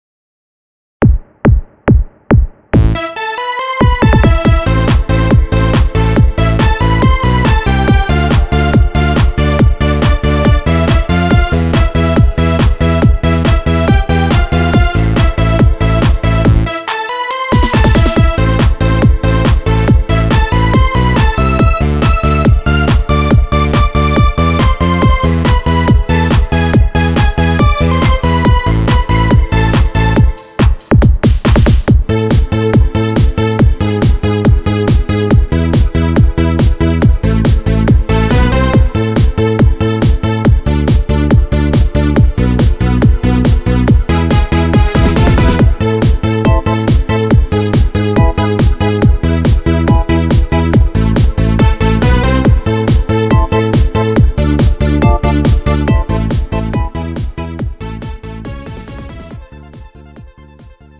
음정 (여자)
장르 가요 구분 Lite MR